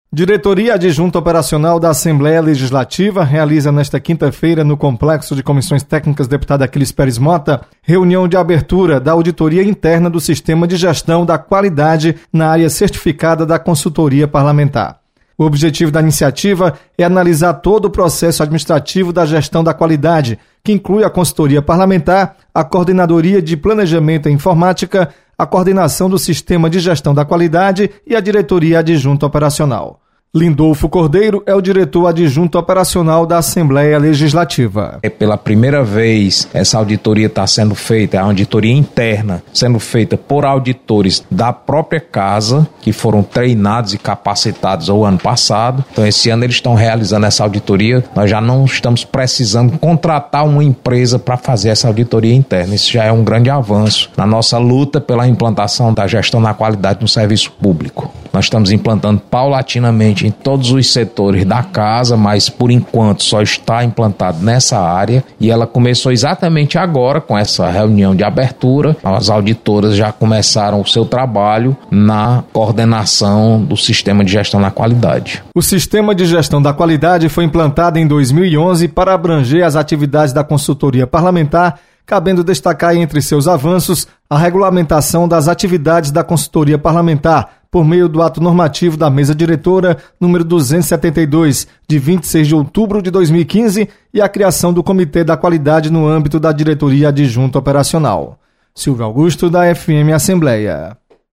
Diretoria Adjunto Operacional inicia auditoria interna. Repórter